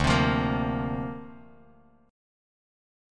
Dm7#5 chord